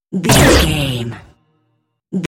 Dramatic hit laser
Sound Effects
Atonal
heavy
intense
dark
aggressive